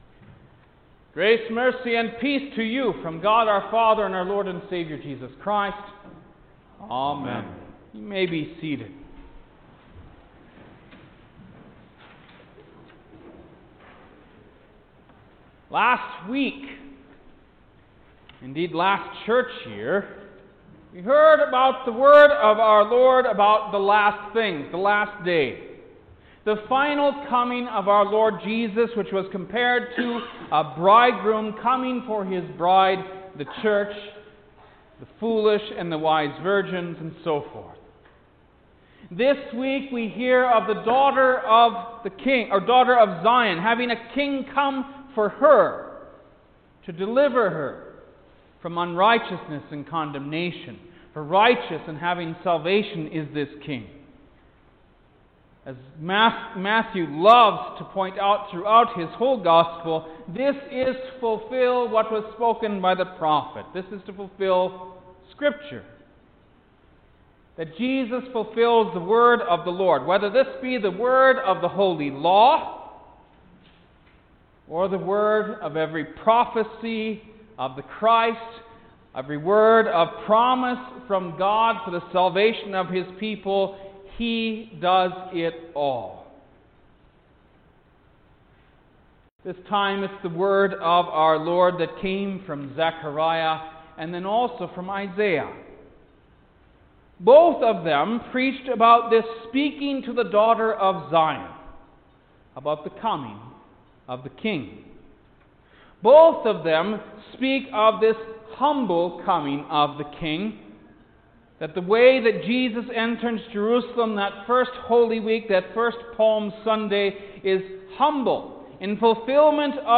November-29-First-Sunday-in-Advent_Sermon.mp3